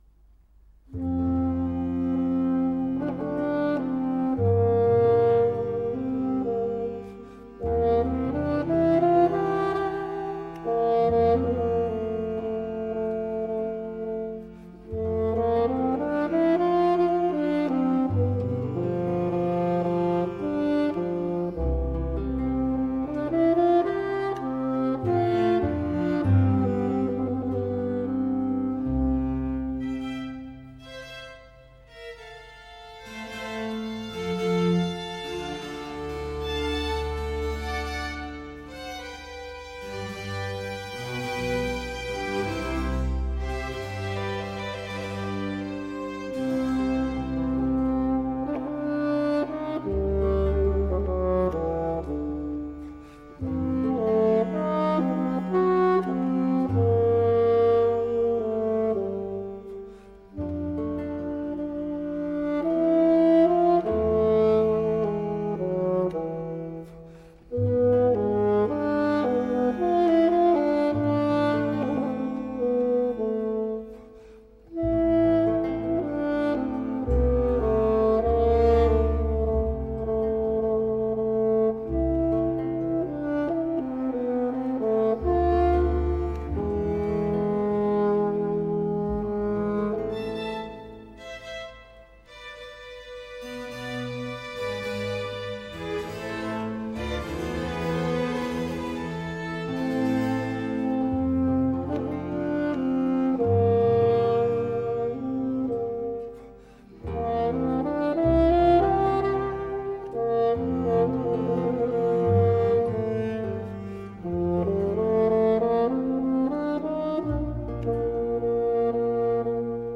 Bassoon concerto
Group: Solo concerto
A concerto for bassoon accompanied by a musical ensemble, typically orchestra.